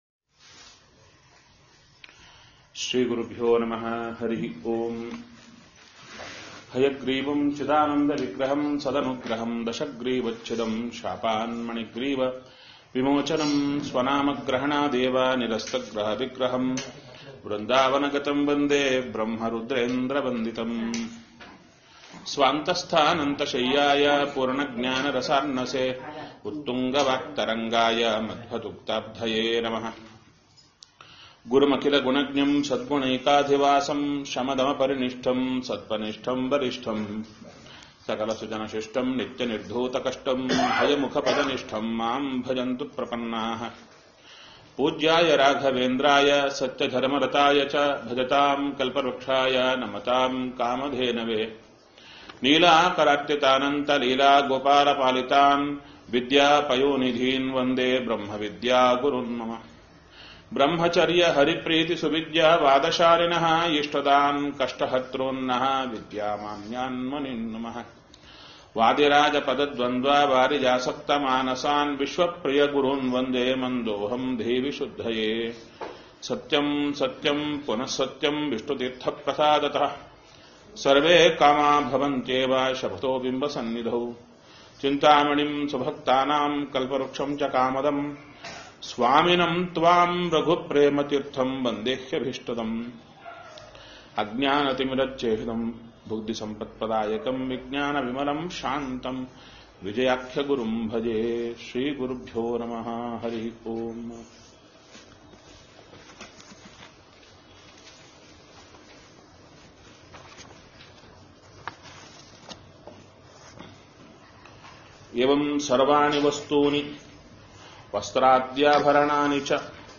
Go Home Pravachana Aakhyana Swapna Vrindavana Akhyana Adhyaya 3 3.M4a Swapna Vrindavana Akhyana Adhyaya 3 3.M4a